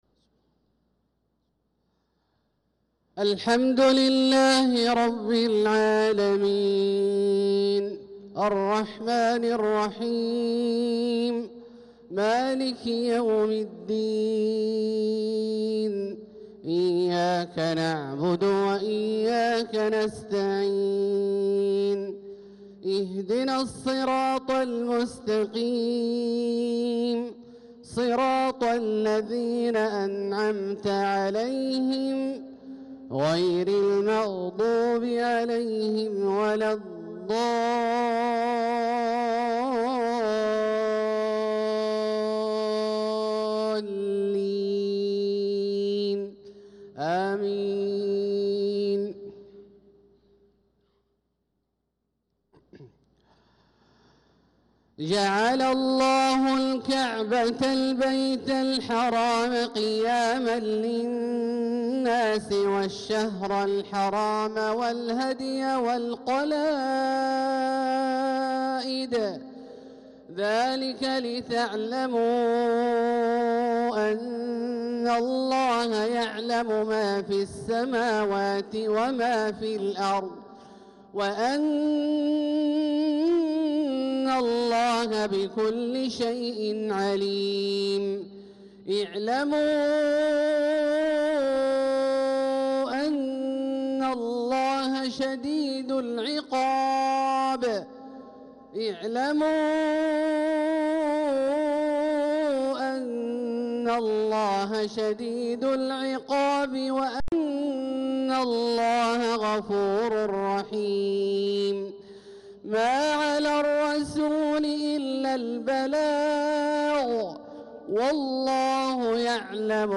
صلاة المغرب للقارئ عبدالله الجهني 5 ذو الحجة 1445 هـ